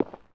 Concret Footstep 03.wav